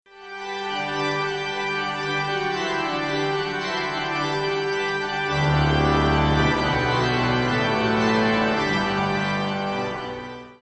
Sound Extracts From the Rieger Organ